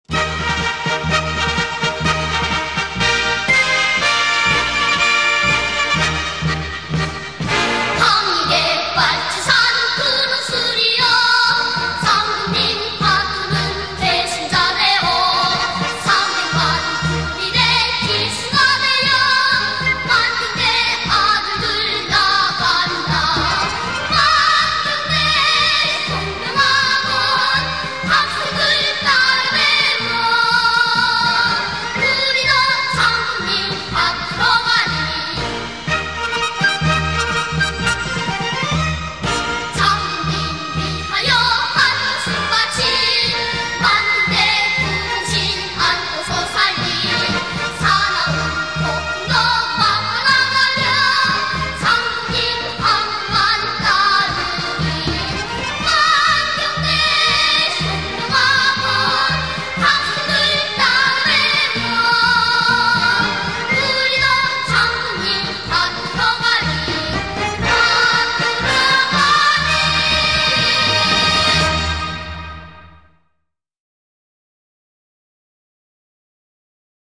Korean Children's music